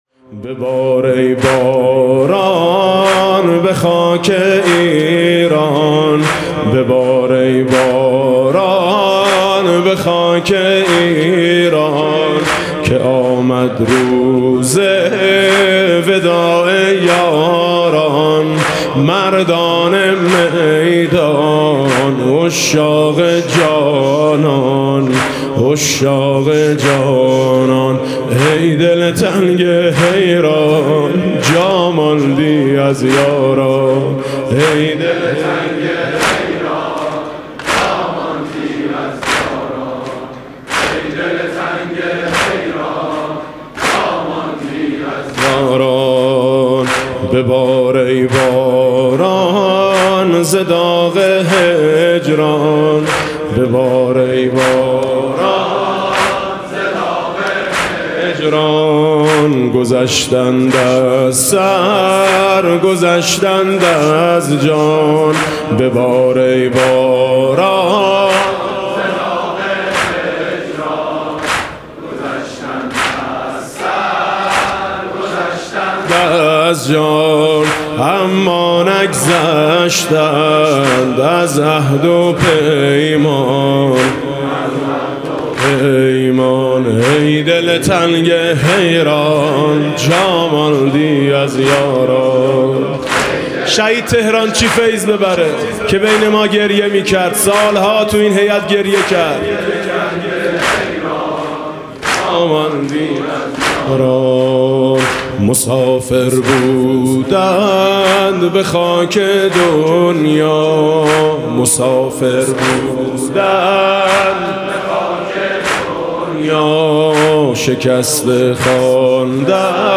نوحه سنّتی